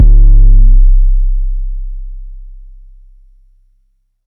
808s
808 (All Ass).wav